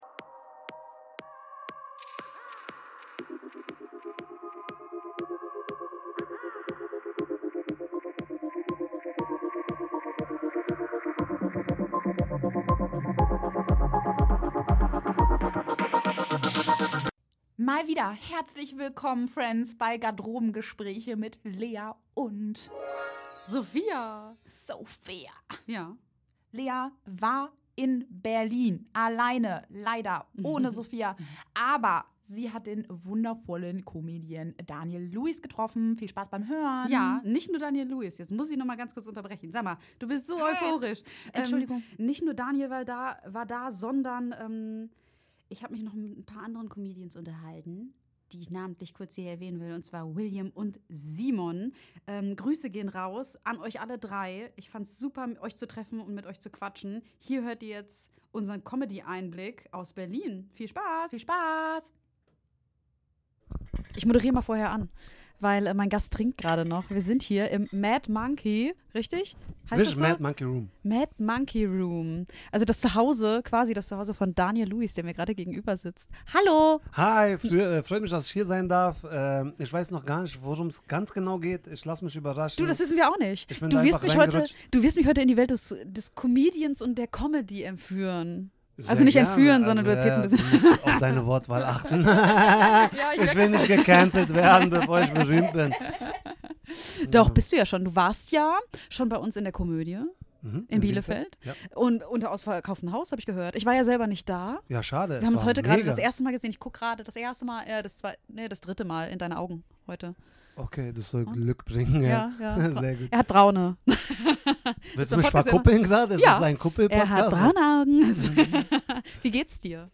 Aus einem geplanten Interview wird ein wilder, unterhaltsamer Wechsel zwischen Showbühne und Backstage-Anekdoten. Diese Folge ist eine improvisierte Momentaufnahme aus der Berliner Comedy-Szene – direkt, ehrlich und natürlich mit einer ordentlichen Portion Humor.